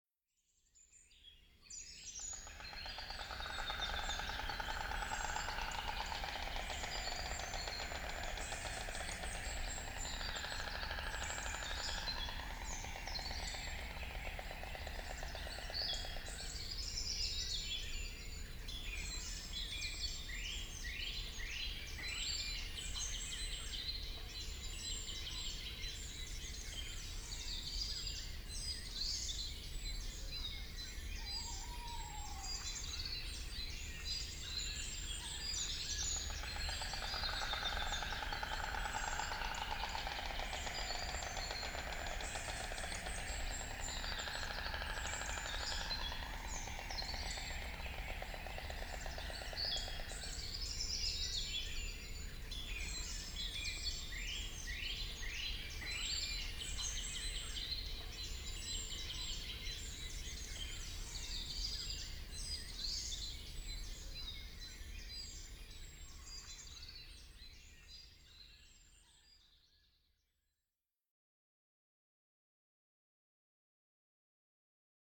valge-toonekurg.ogg